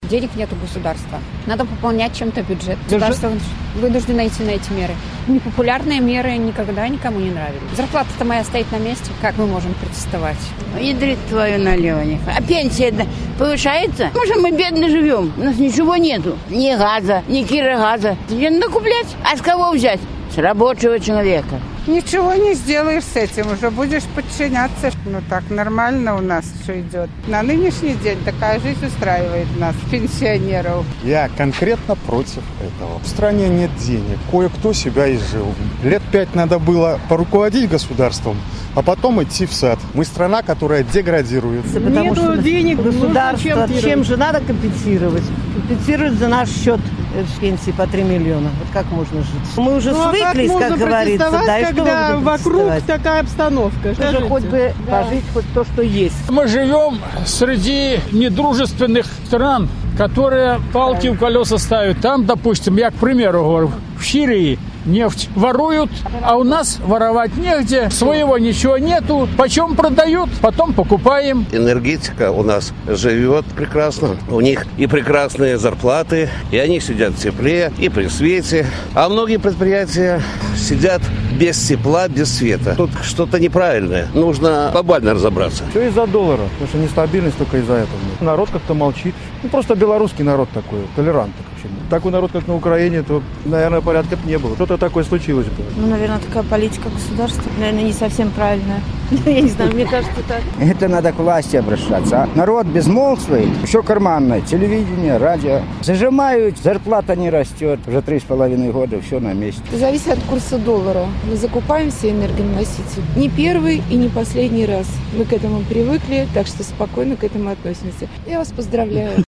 Апытаньне ў Магілёве.